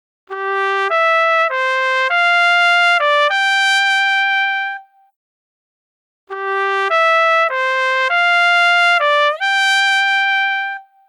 A) Détaché / Legato / Portamento :
Plus le passage entre 2 notes est doux (chevauchement long et vélocité faible), plus le portamento est lent et accentué :
A gauche le legato, à droite un exemple de portamento,
Portamento.mp3